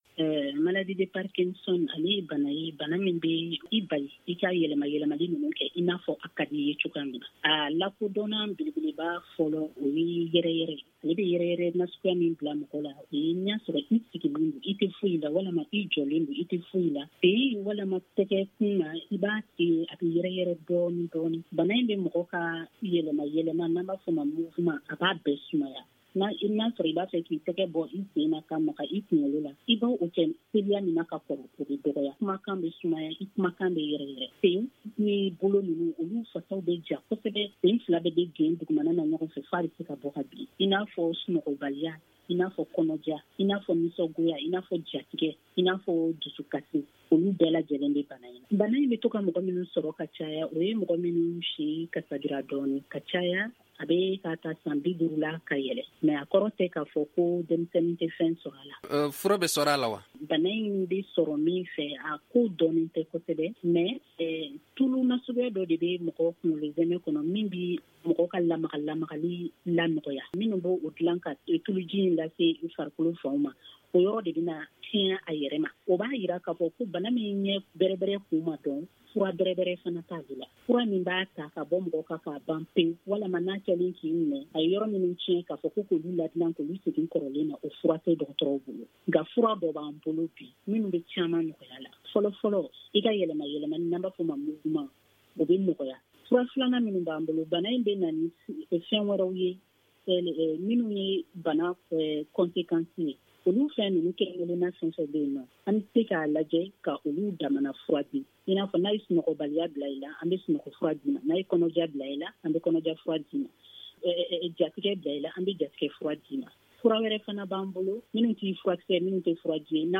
Magazine en bambara: Télécharger